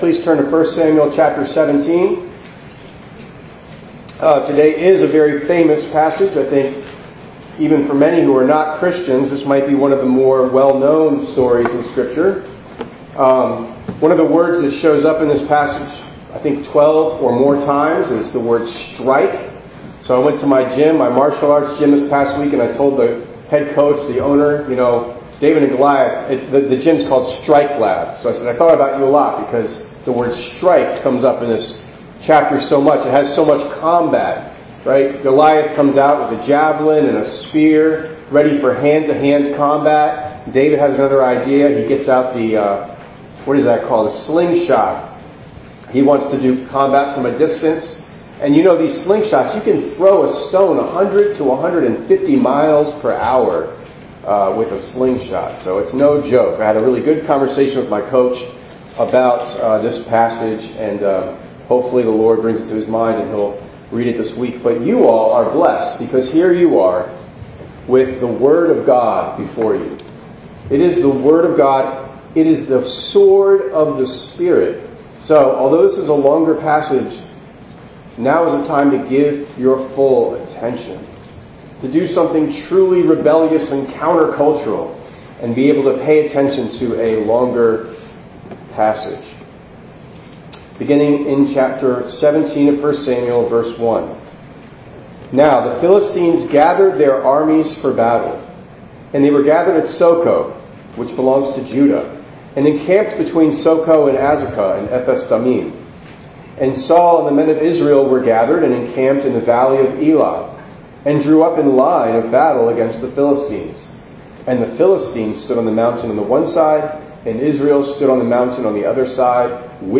5_11_25_ENG_Sermon.mp3